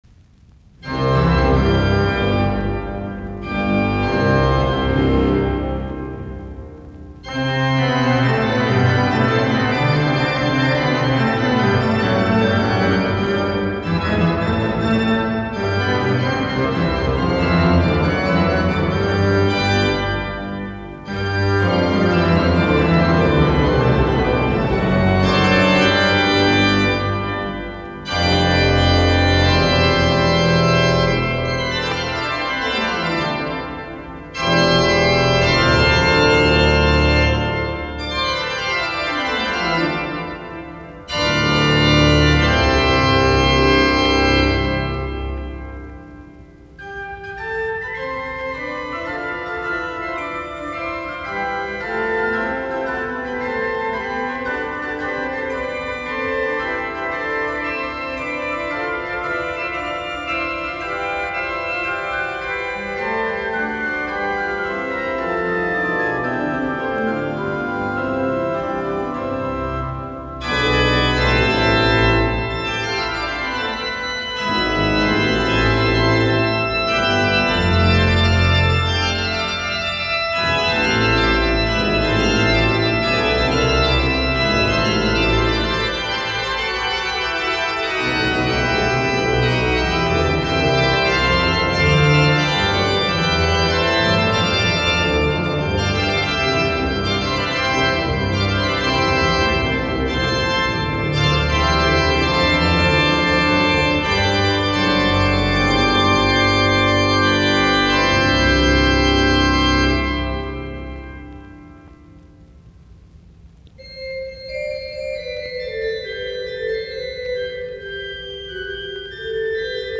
Preludit, urut, BuxWV137, C-duuri
Biggs, E. Power ( urut ) The Art of the Organ.
Soitinnus: Urut.
Tukholma, Oskarskyrkan.